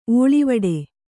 ♪ ōḷivaḍe